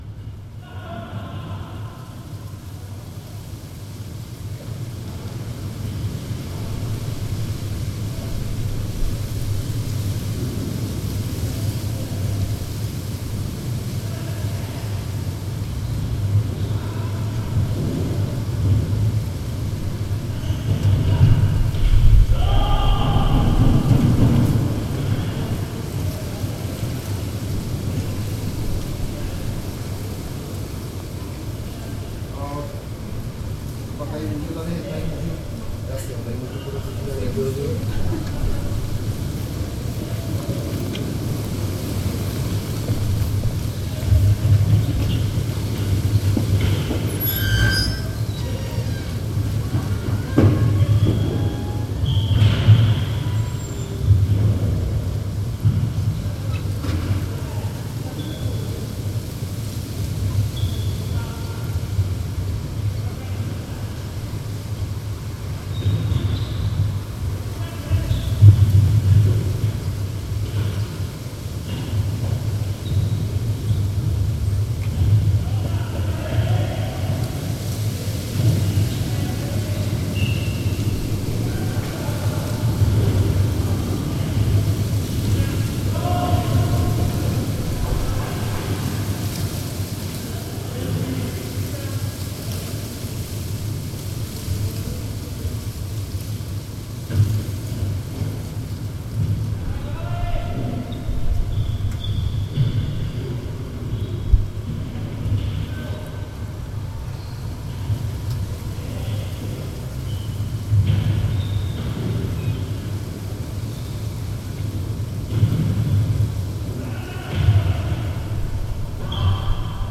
fotbal v tělocvičně na Basilejském náměsti
Tagy: interiéry lidé příroda sport okna a dveře
Záznam fotbalového zápasu v tělocvičně přes okno ve větrném mrazivém večeru.